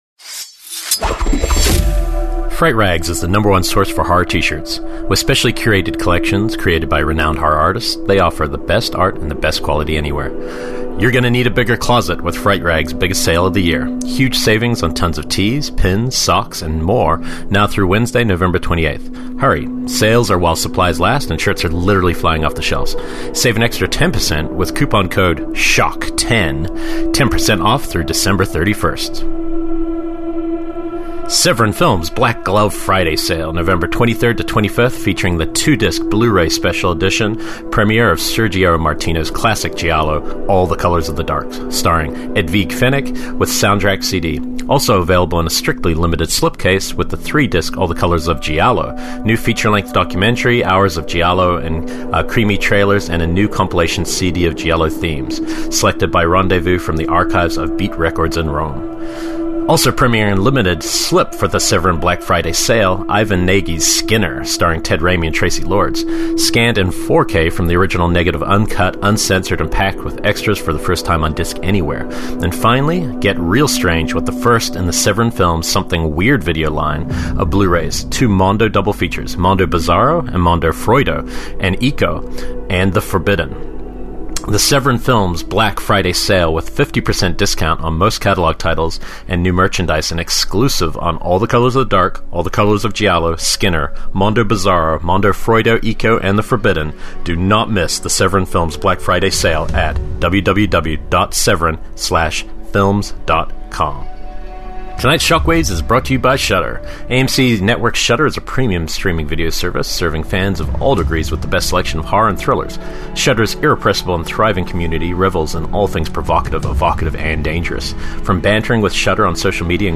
Episode 123: Live from the Knoxville Horror Film Fest!